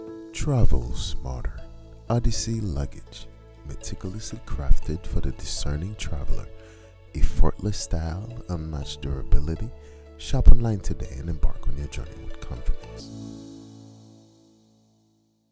Online Ad: Odyssey Luggage: confident, sophisticated, aspirational, wanderlust and adventure